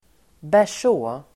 Uttal: [ber_s'å:]